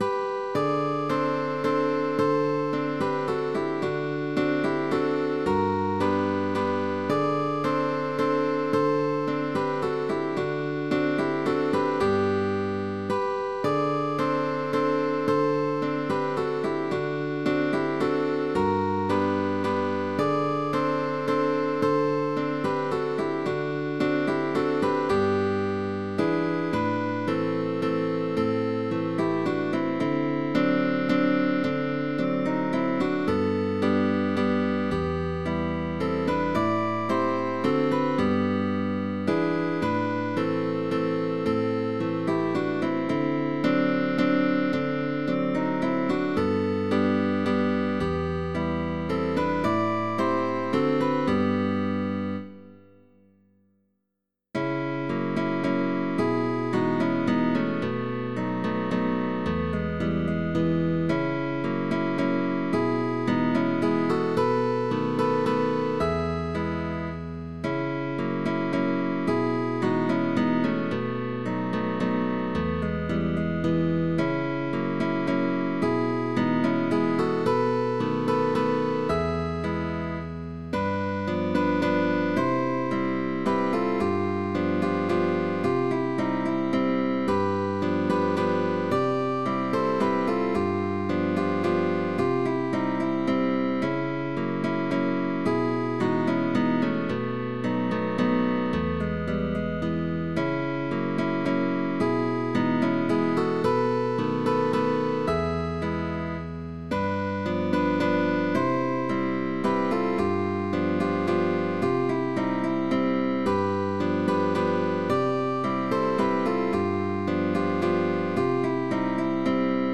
Cambios de posición, ligados, cejillas, posiciones agudas,…